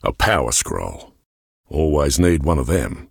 Power_scroll.ogg